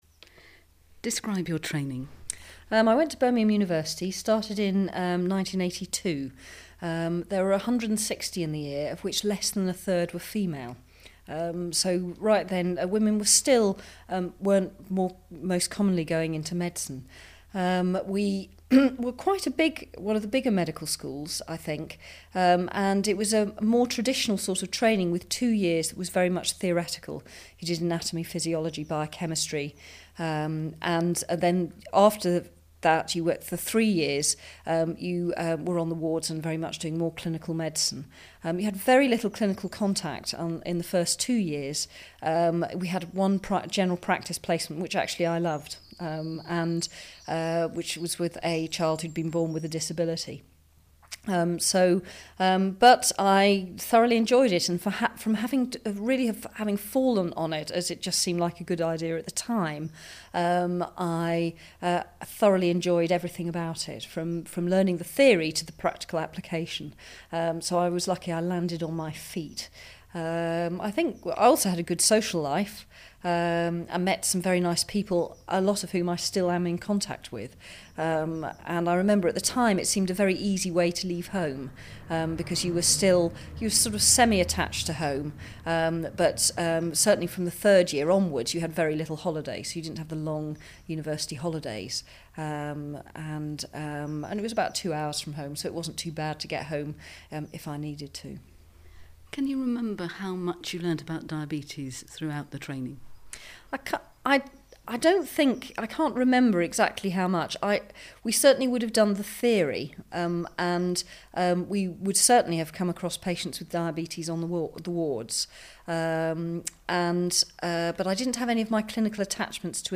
Available interview tracks